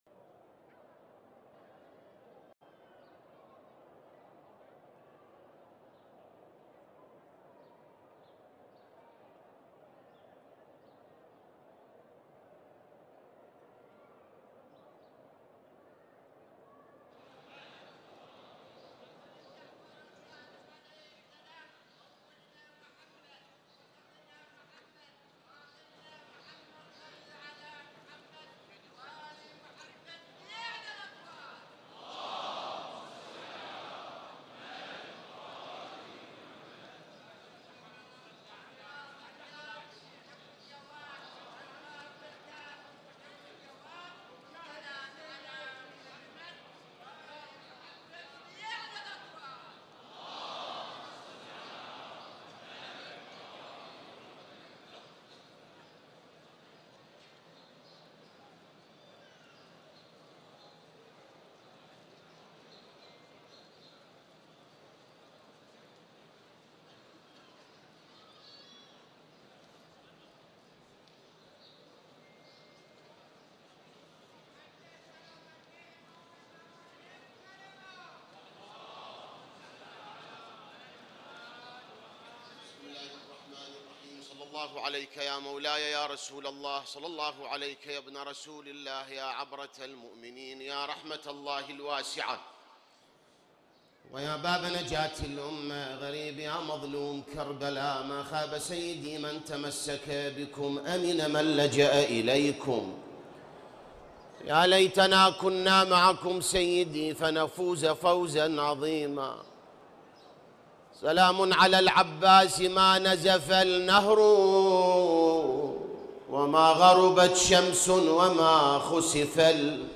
مقتل العباس || من الصحن الحسيني ٧ محرم ١٤٤٧ هـ ||